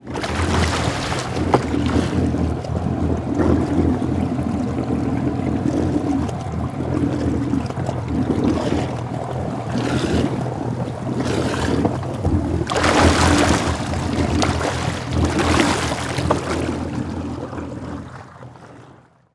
Шум пузырей под двигателем гидроцикла